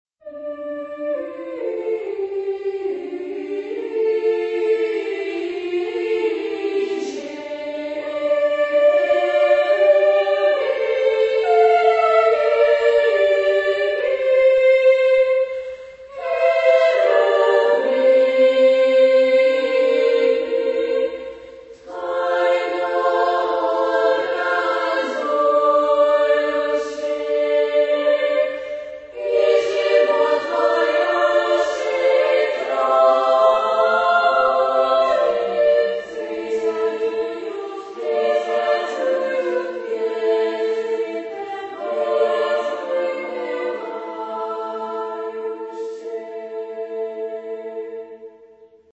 Género/Estilo/Forma: Sagrado ; Himno litúrgico (ortodoxo) ; Ortodoxa
Carácter de la pieza : solemne ; piadoso
Tipo de formación coral: SSAA  (4 voces Coro femenino )
Tonalidad : re menor